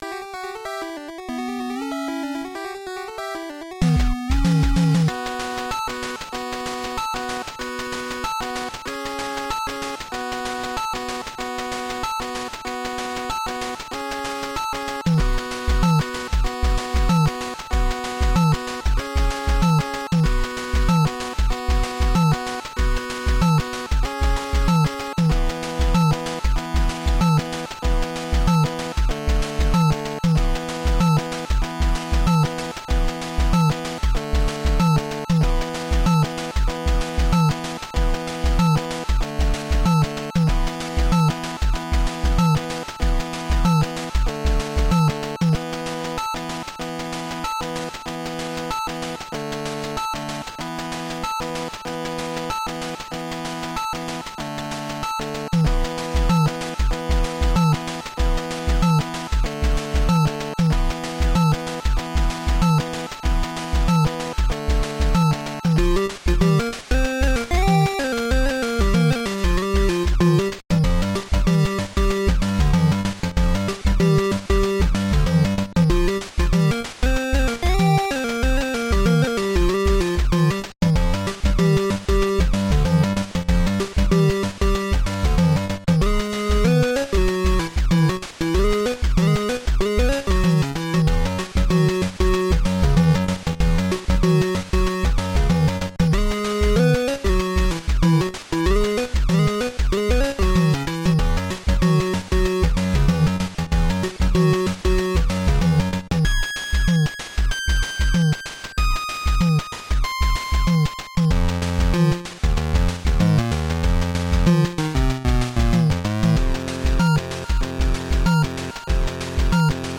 Sequenced in 0CC-FamiTracker using the Konami VRC6 chip.